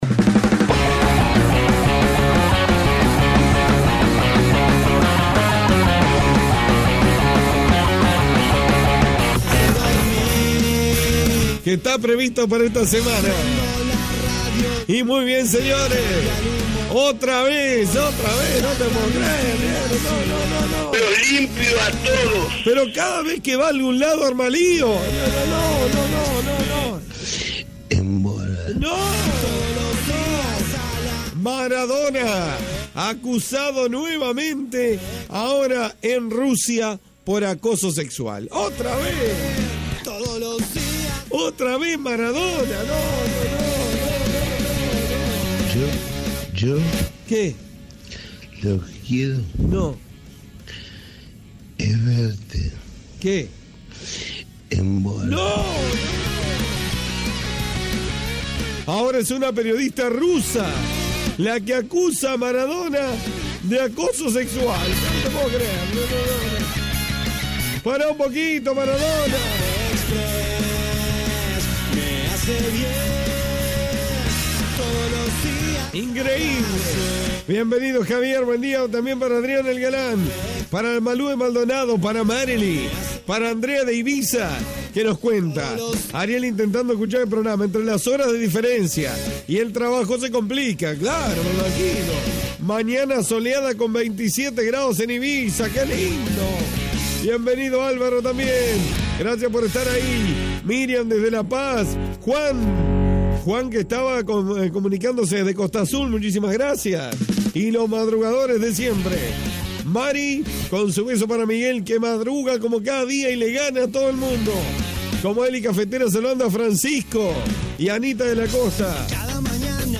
Tengo tos, estornudos, congestión nasal, dolor de cabeza, chuchos, ¡PERO NO IMPORTA! CAFÉ EXPRESS CON TODO.